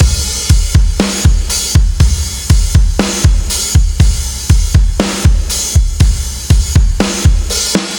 Ударные и барабаны: Poisonous A (Rock Alternative)